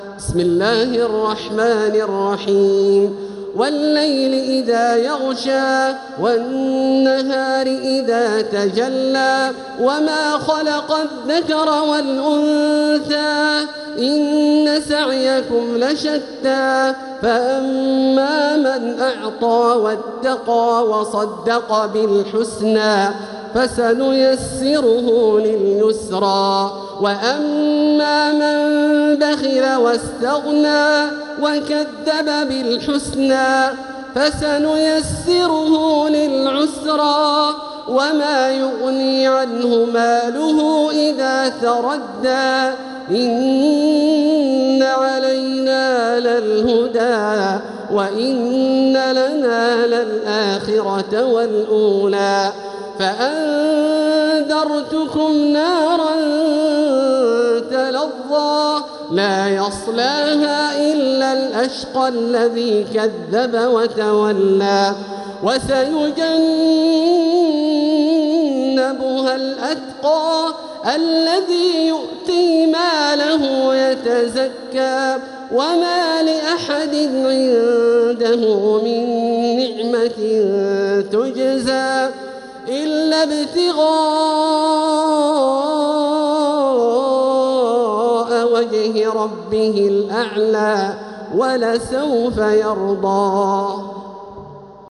سورة الليل | مصحف تراويح الحرم المكي عام 1446هـ > مصحف تراويح الحرم المكي عام 1446هـ > المصحف - تلاوات الحرمين